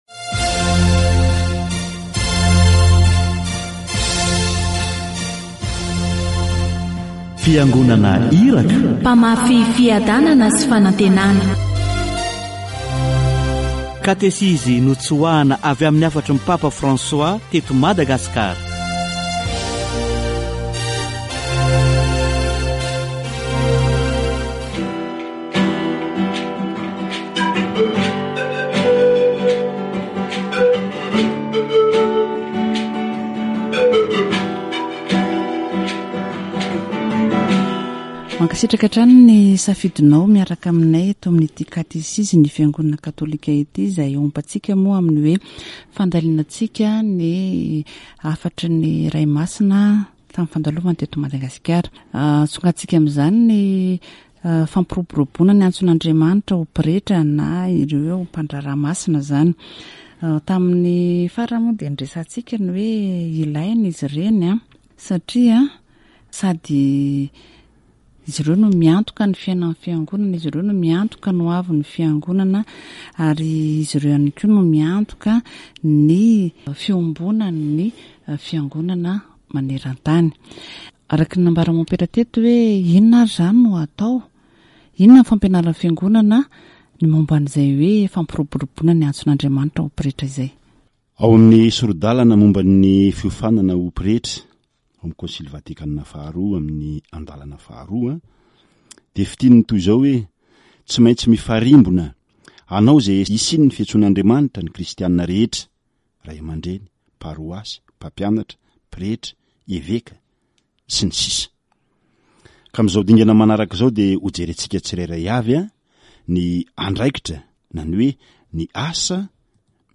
Katesizy momba ny fampiroboroboana ny antson'Andriamanitra ho pretra